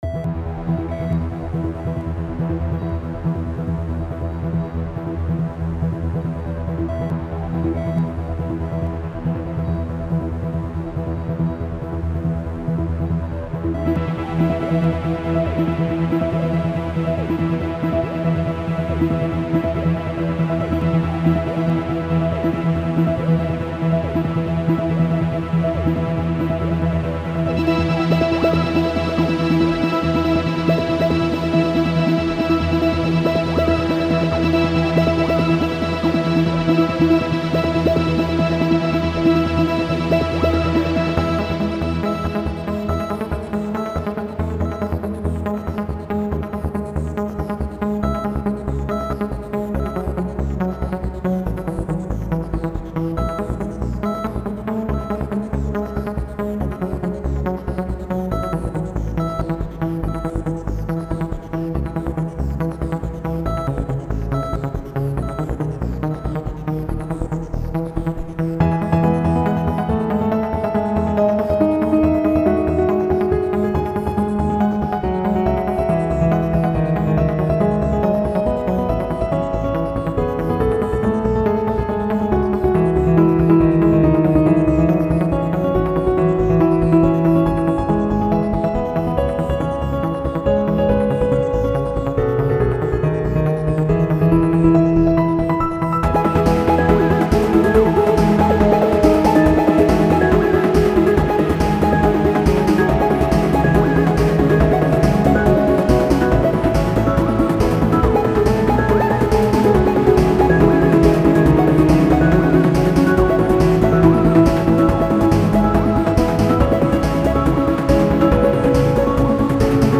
track with Space accent.